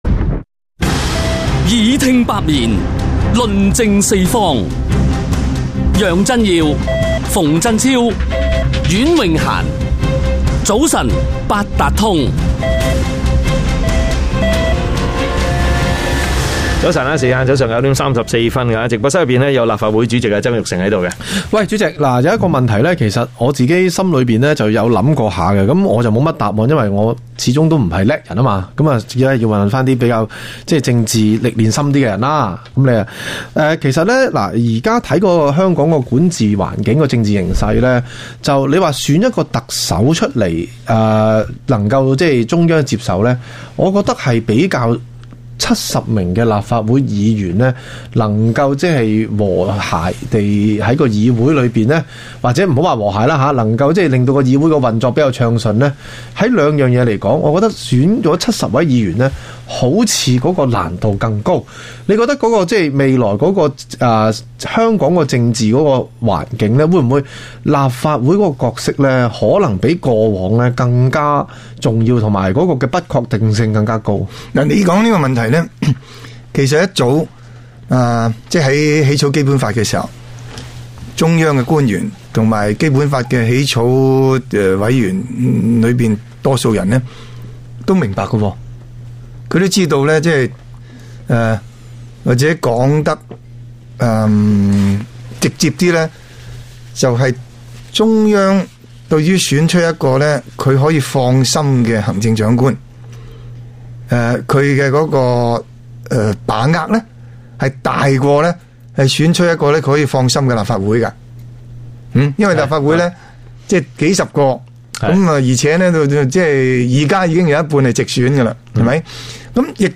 DBC數碼電台《早晨八達通》訪問